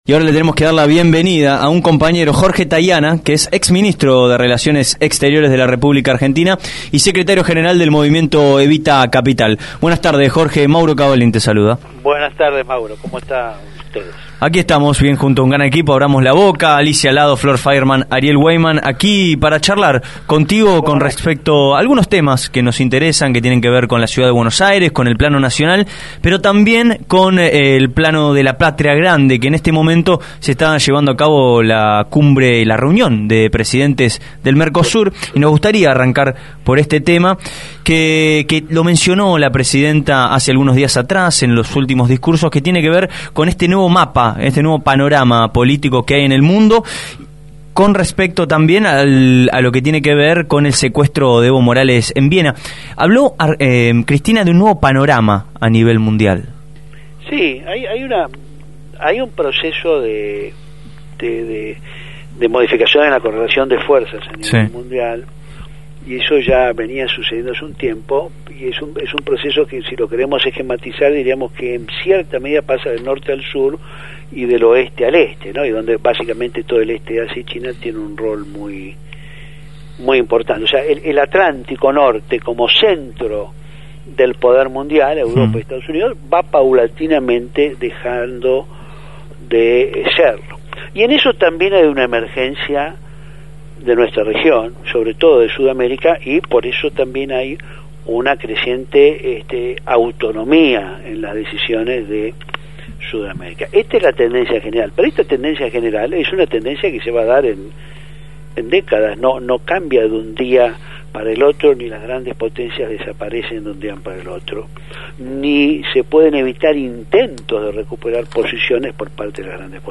Jorge Taiana, ex canciller de la Argentina y Secretario General del Movimiento Evita Capital, habló con Abramos la Boca.